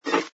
sfx_pick_up_bottle05.wav